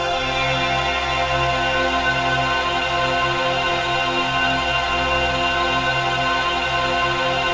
ATMOPAD30 -LR.wav